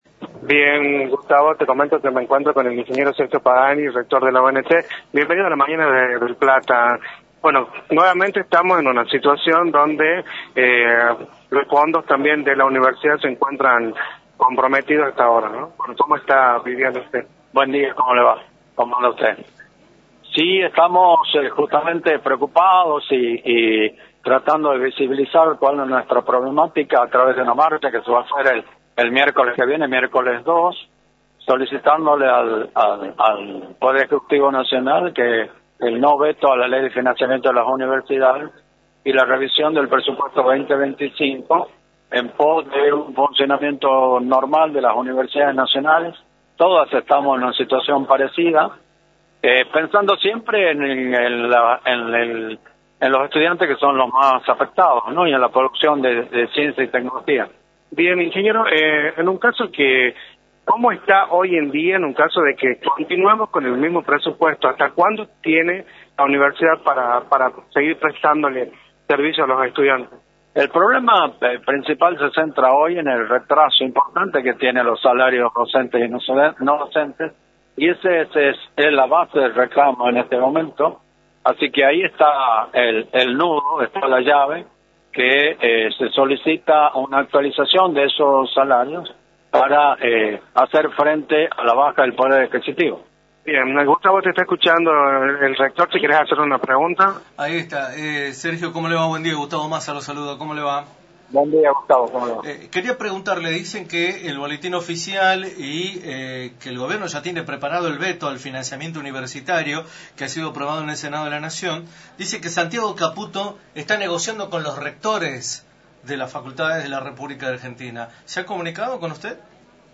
“Estamos preocupados por esta situación y solicitamos que el Presidente Milei no vete la ley de financiamiento a universidades en pos del funcionamiento normal de las universidades, pensando siempre en los estudiantes y en el desarrollo de la ciencia y la tecnología” señaló Sergio Pagani en “La Mañana del Plata”, por la 93.9.